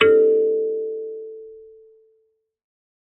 kalimba2_wood-A4-pp.wav